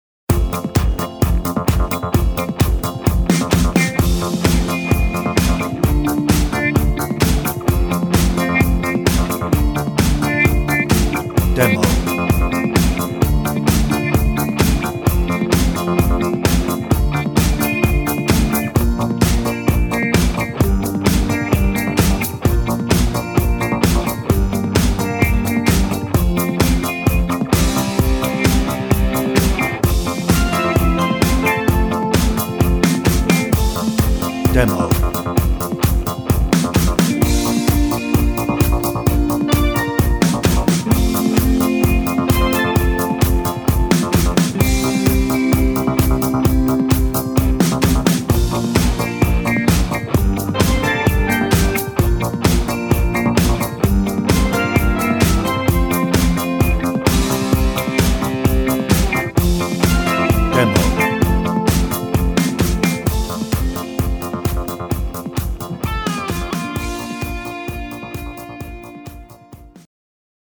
No ref vocal
Instrumental